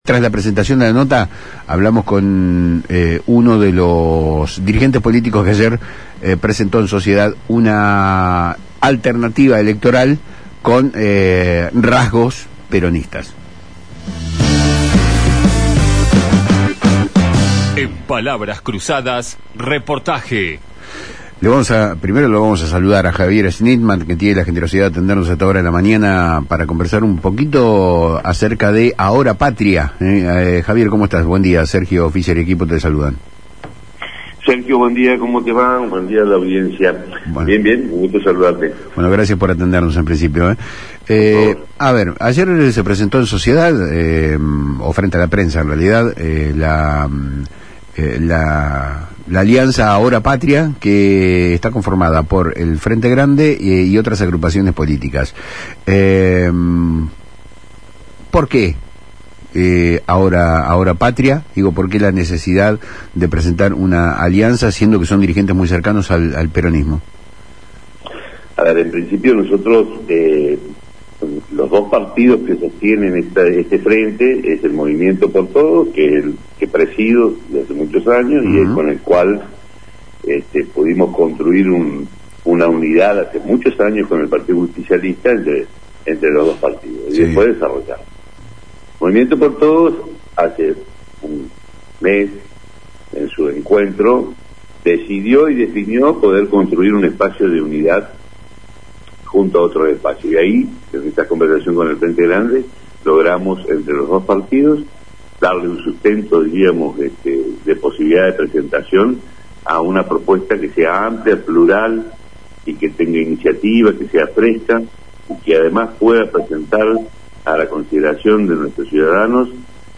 en diálogo con Palabras Cruzadas por FM Lit